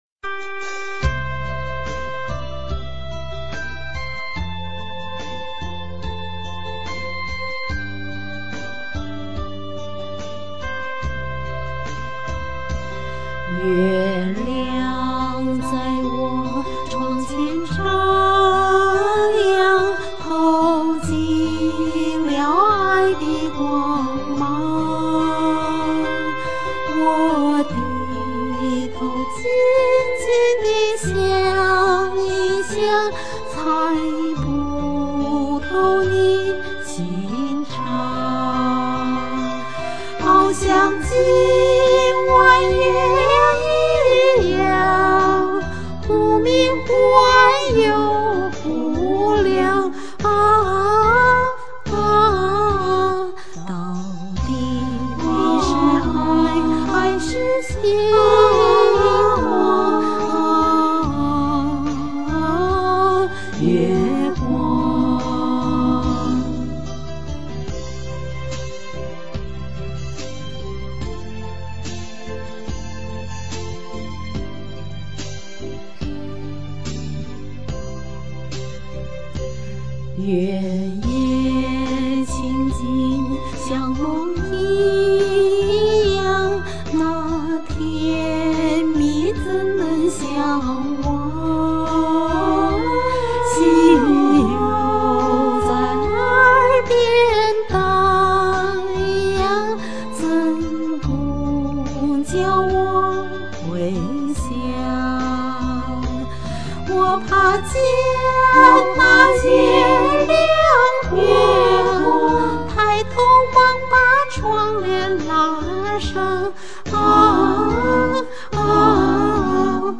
這個伴奏不太好，網上有比這音質好的伴奏可我又無法下載。